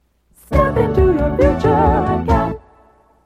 Promotional Audio/Radio Jingle
audio cassette